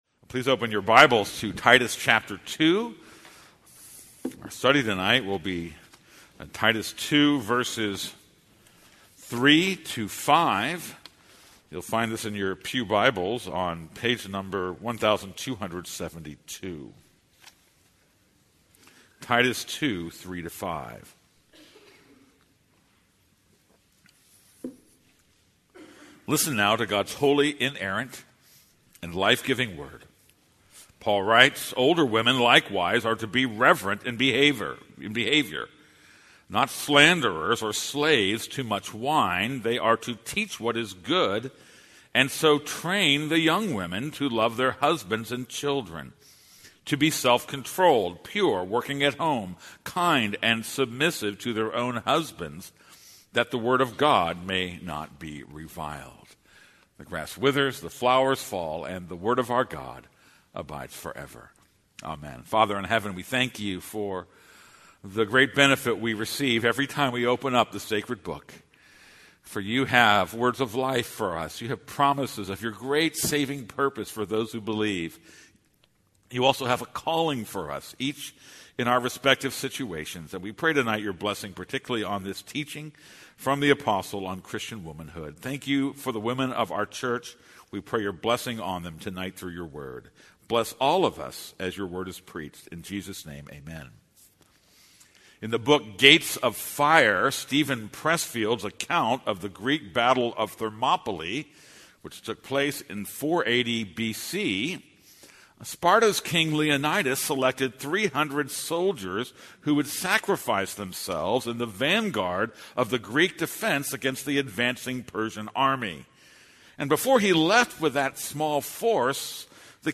This is a sermon on Titus 2:3-7.